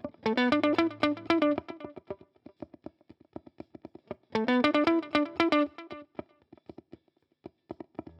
11 Pickin Guitar PT2.wav